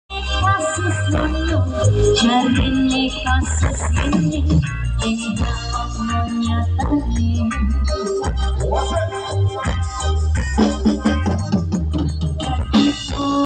demo produk SPL audio Surabaya sound effects free download
di acara nguntir bareng aniversary ke 5tahun ppssngawi